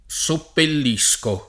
seppellire v.; seppellisco [Seppell&Sko], ‑sci — forma popolareggiante dell’uso più ant., soppellire: soppellisco [